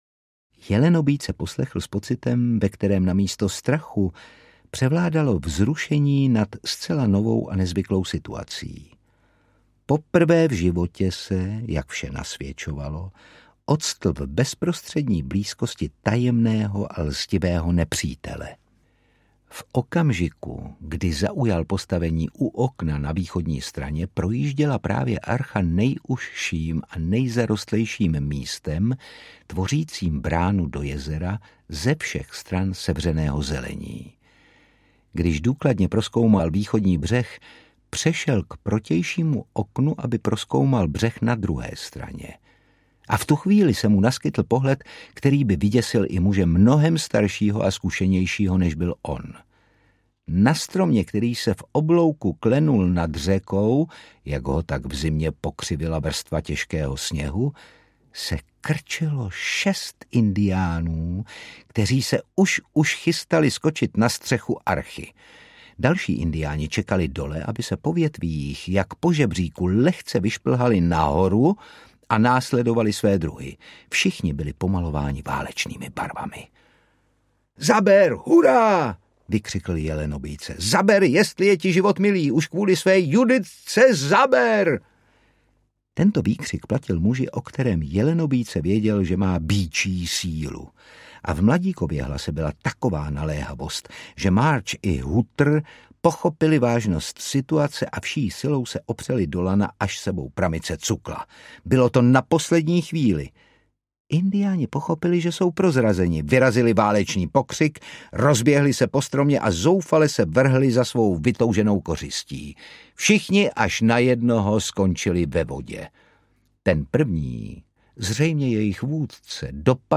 Lovec jelenů audiokniha
Ukázka z knihy
Čte Otakar Brousek.
Vyrobilo studio Soundguru.
• InterpretOtakar Brousek